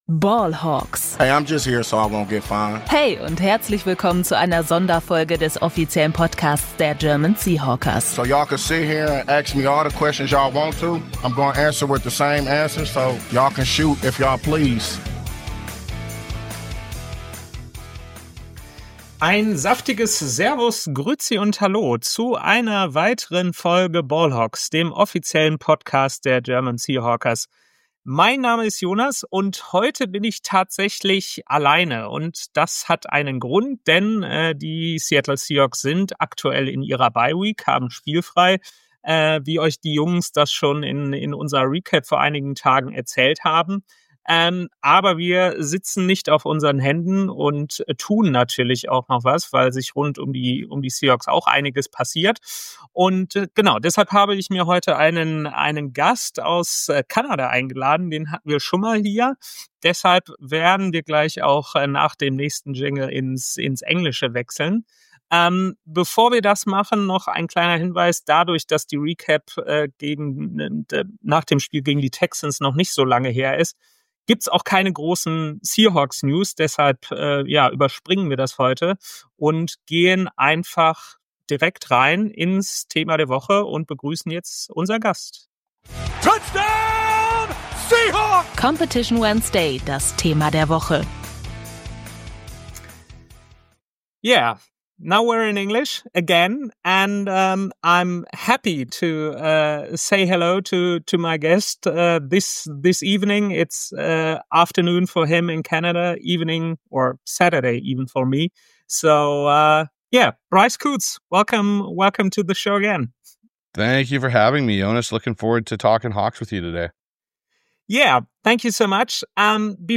Begrüßung Thema der Woche: Crossover-Podcast mit The Hawks Eye [1:26] Warum hat die NFL-Welt die Seahawks 2025 nicht auf dem Radar?